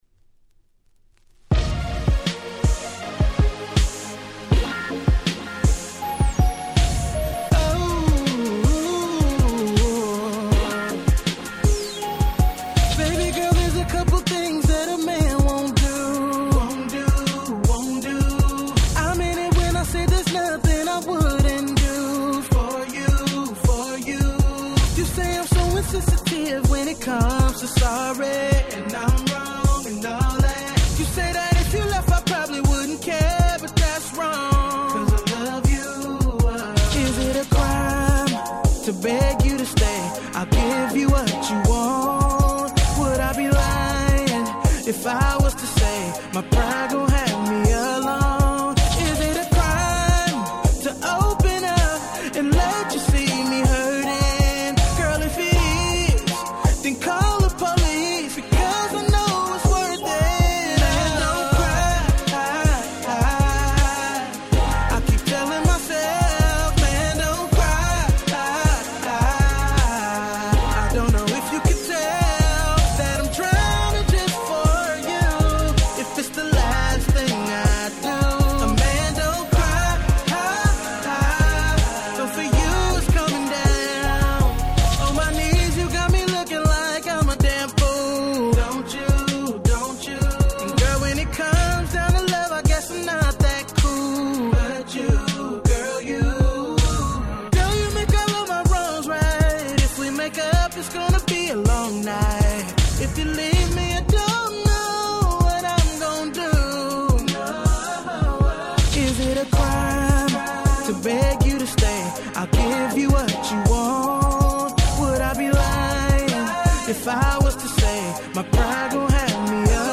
09' Nice R&B Compilation !!